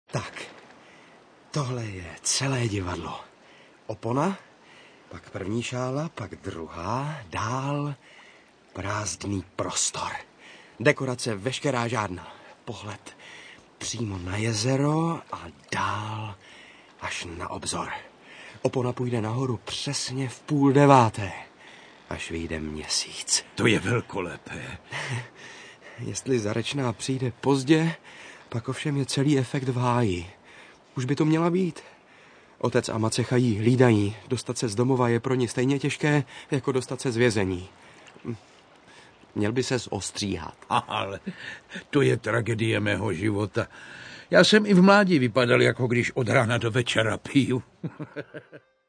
Rozhlasová nahrávka divadelnej hry A. P. Čechova.
Vypočujte si ukážku audioknihy